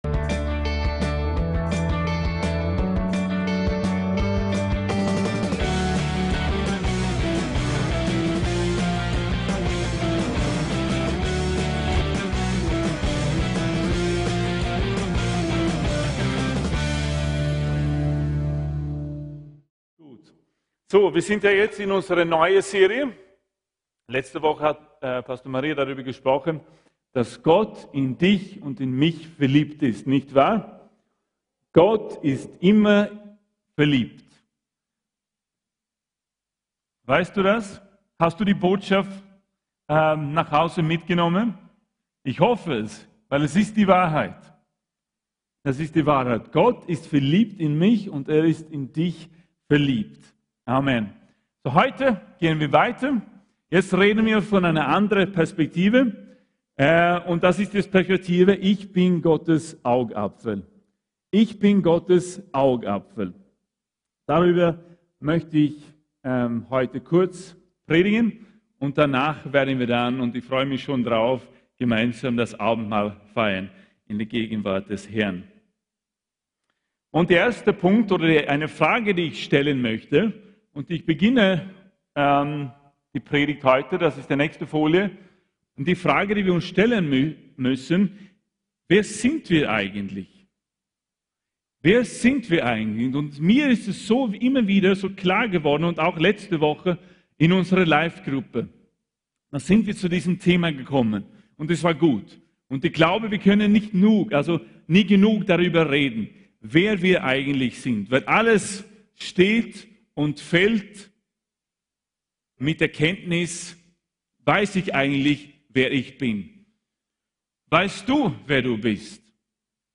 ICH BIN GOTTES AUGAPFEL ~ VCC JesusZentrum Gottesdienste (audio) Podcast